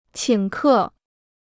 请客 (qǐng kè): to give a dinner party; to entertain guests.